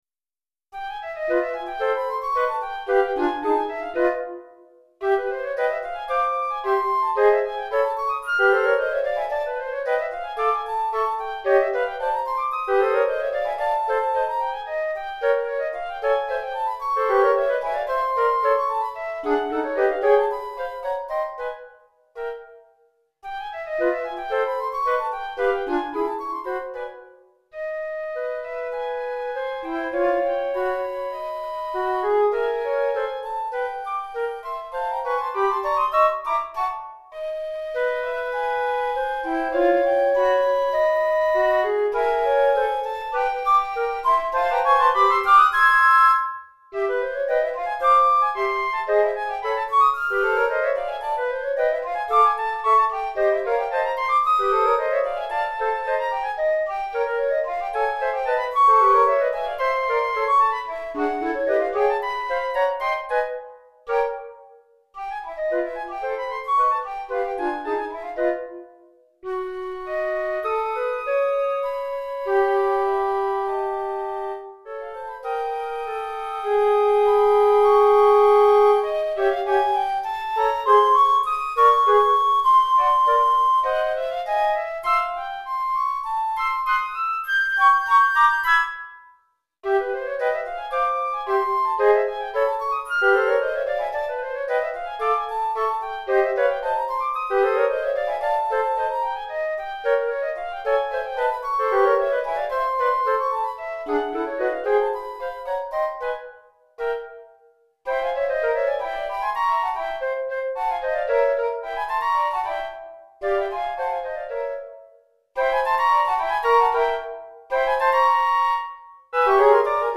3 Flûtes Traversières